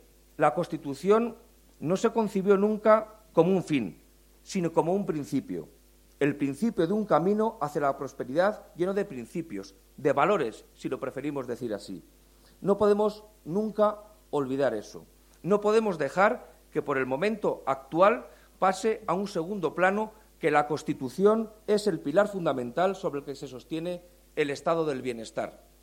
El delegado de la Junta en Guadalajara, Alberto Rojo, habla de la Constitución como pilar fundamental del Estado de Bienestar.